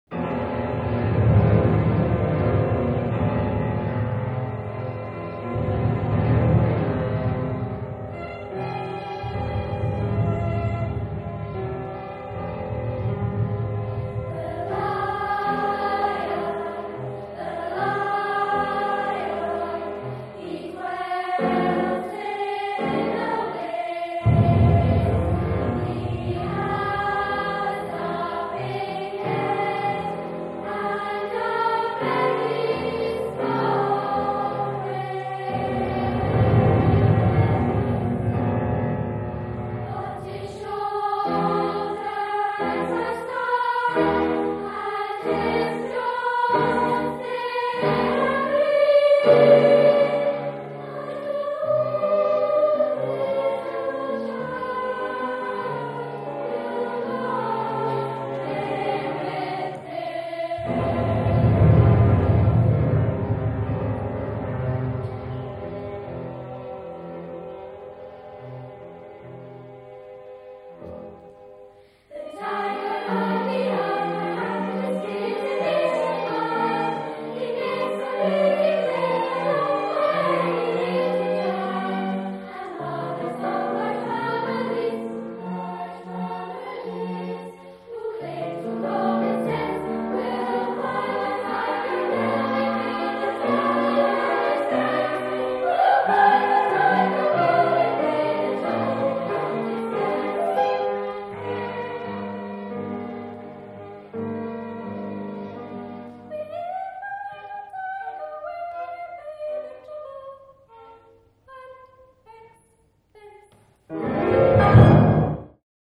For SA youth choir, string quartet, piano and percussion.